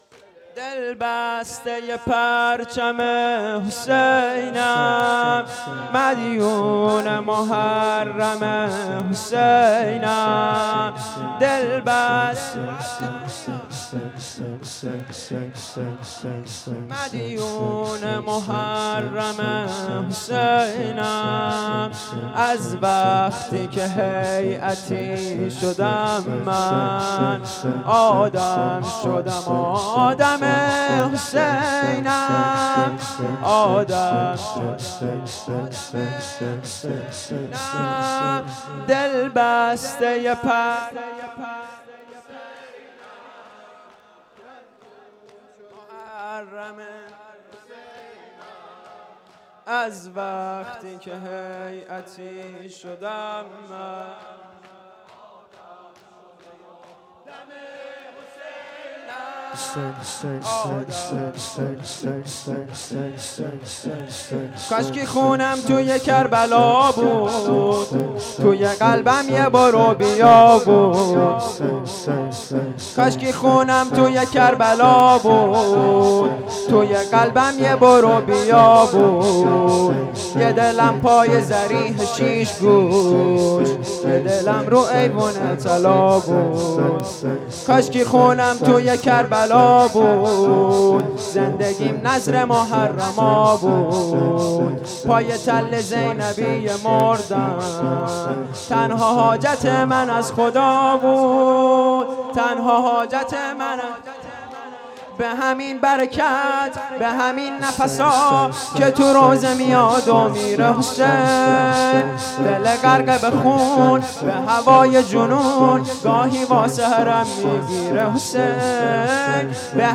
شور
شب دوازدهم ماه محرم سال 94.mp3